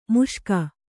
♪ muṣka